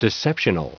Prononciation du mot deceptional en anglais (fichier audio)
Prononciation du mot : deceptional